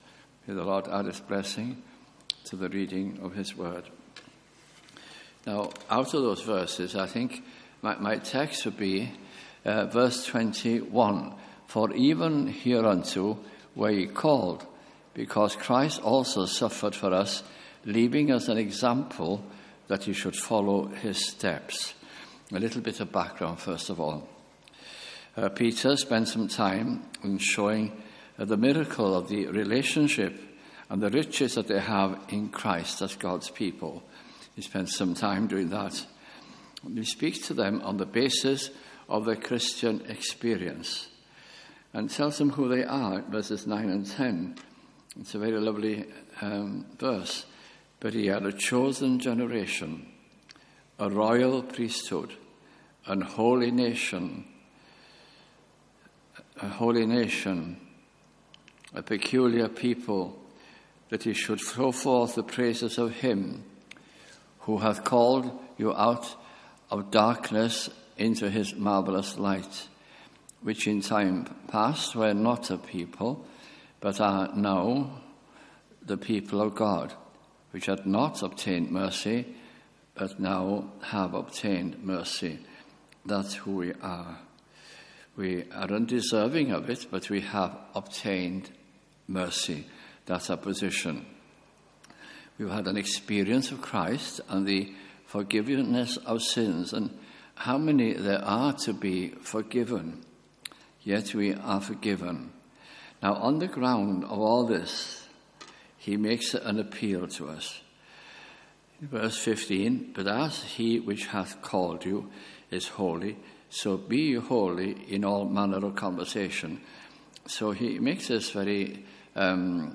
» 1 Peter » Bible Study Series 2010 - 2011 » at Tabernacle Cardiff